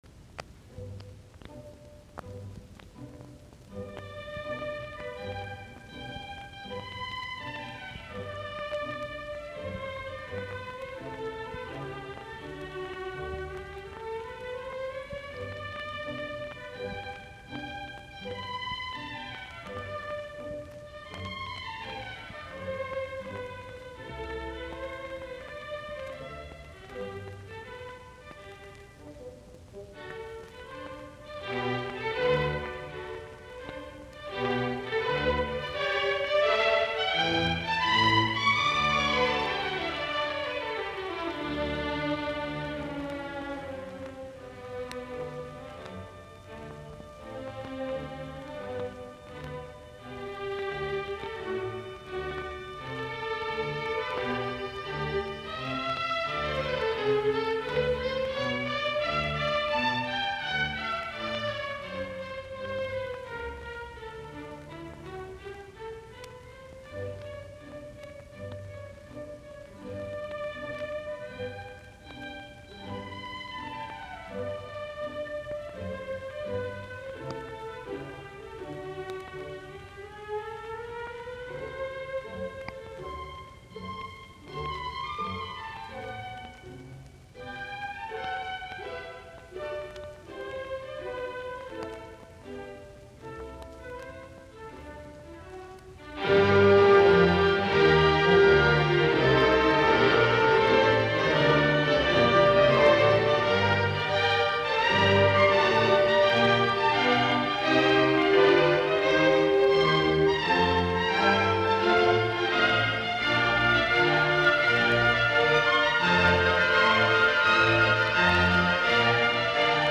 Sinfoniat
D-duuri
Adagio - Presto